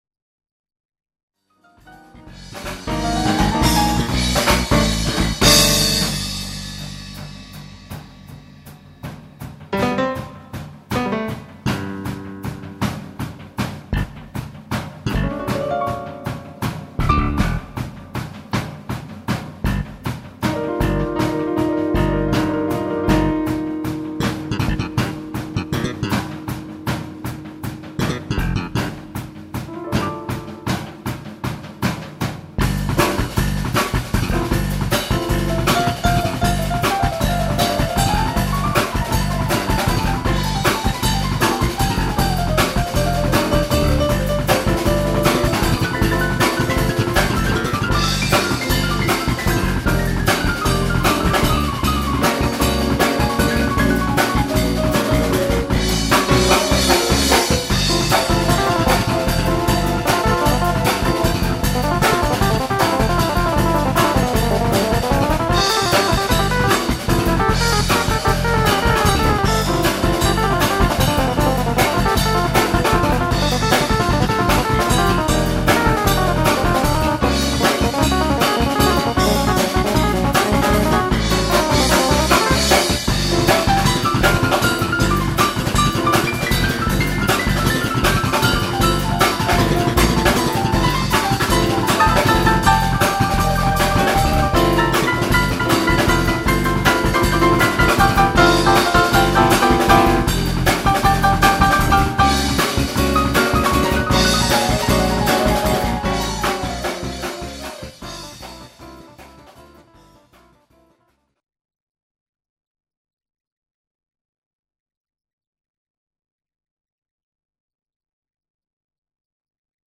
drums, keyb., synth., synthbass